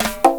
TIMB+PERC1-L.wav